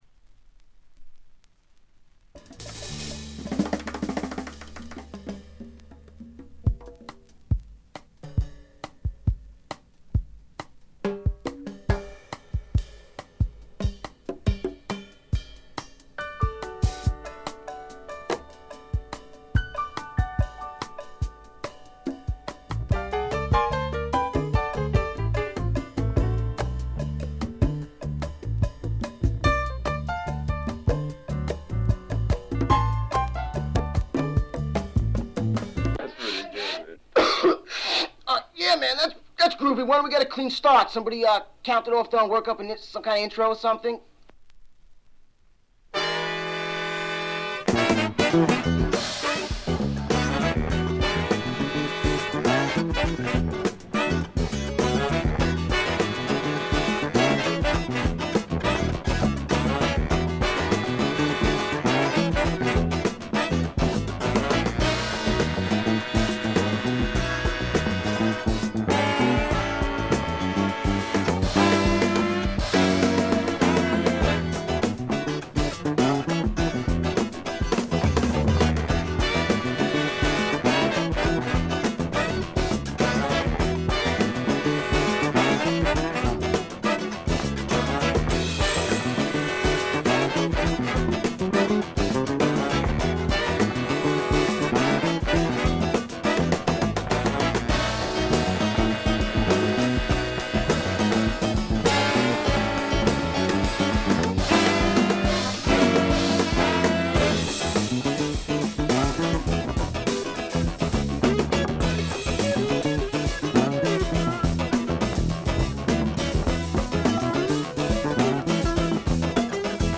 ほとんどノイズ感なし。
よりファンキーに、よりダーティーにきめていて文句無し！
試聴曲は現品からの取り込み音源です。